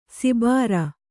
♪ sibāra